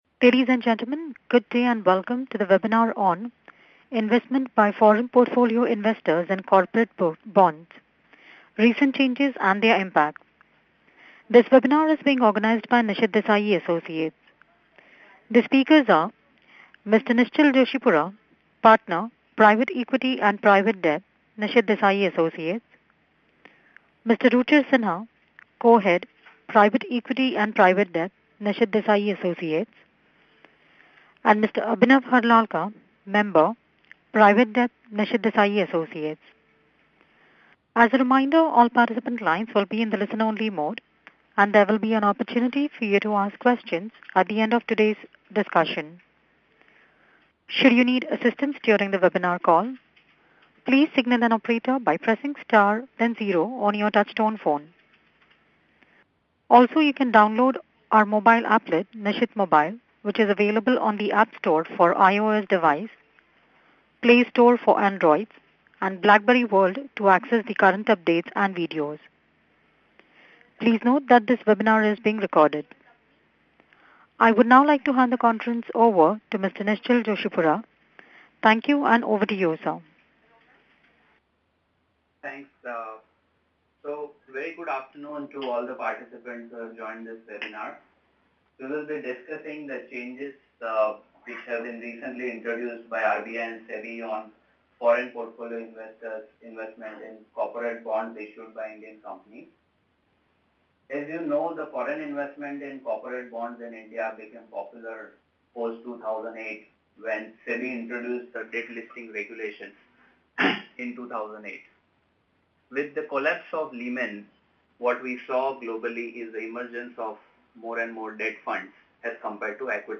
Webinar: Investment by Foreign Portfolio Investors in corporate bonds: Recent changes and their impact (Thursday, February 19, 2015)